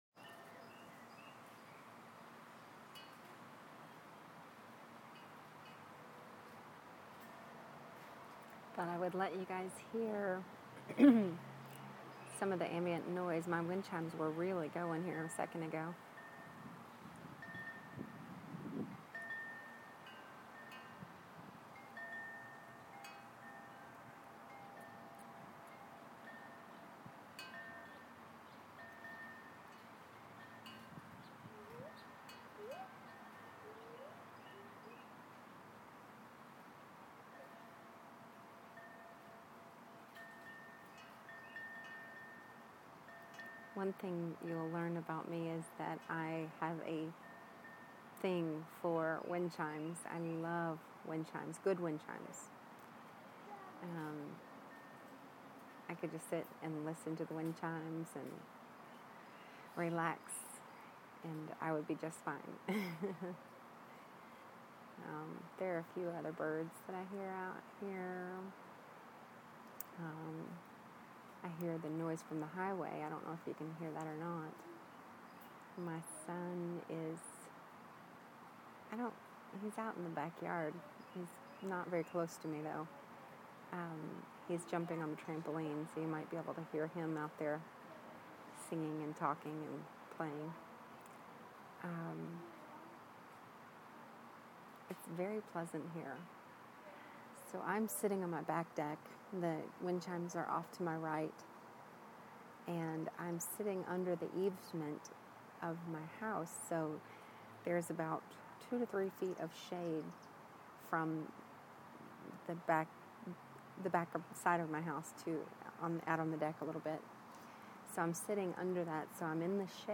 backyard sounds